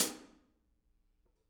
R_B Hi-Hat 02 - Close.wav